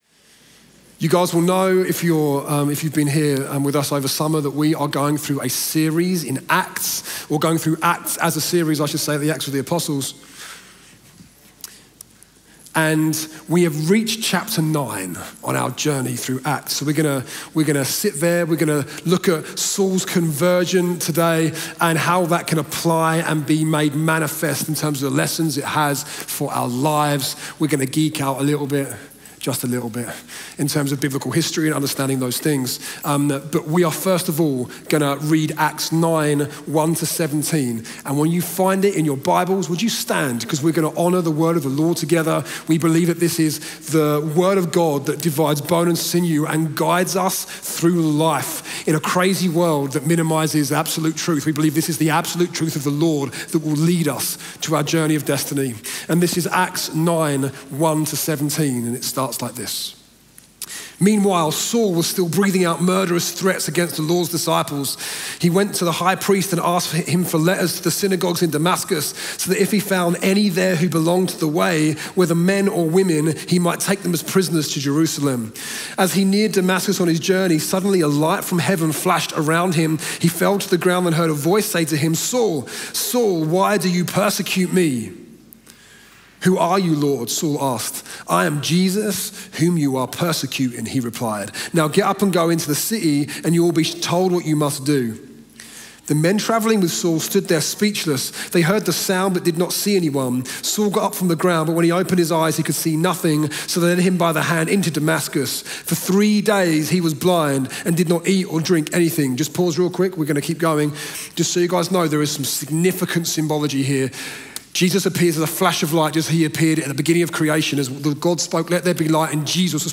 Chroma Church Live Stream
Sunday Sermon The Redemption Of Saul